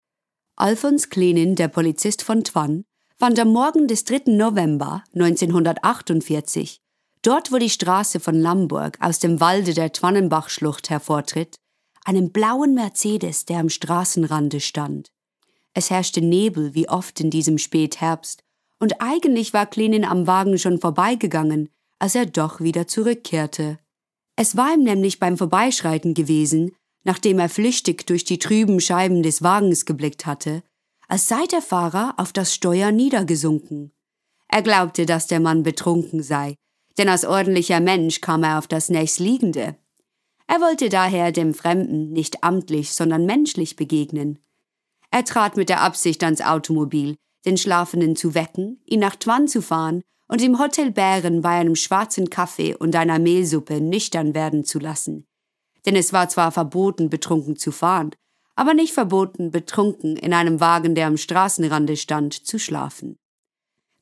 European, German, Swiss-German, Female, Home Studio, 20s-30s